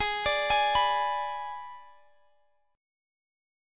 Listen to Abdim strummed